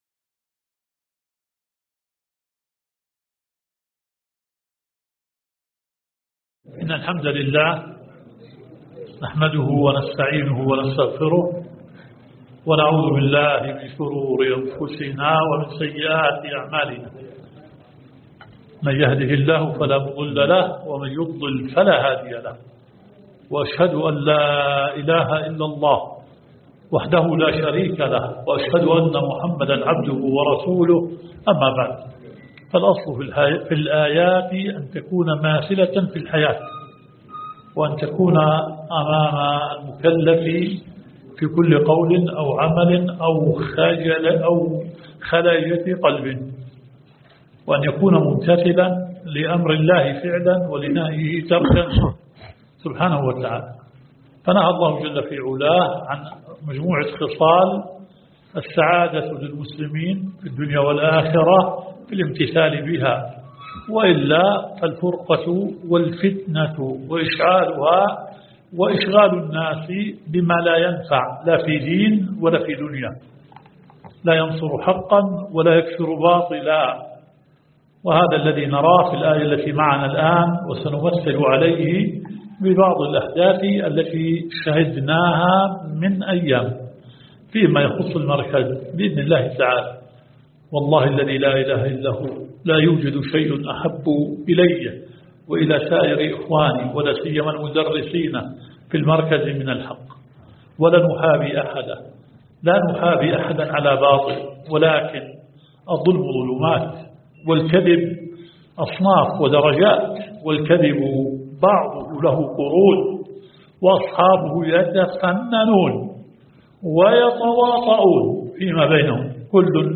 نيل المرام في شرح آيات الأحكام الدورة العلمية السابعة و العشرون المقامة في مركز الإمام الألباني للدراسات والبحوث // المحاضرة الثانية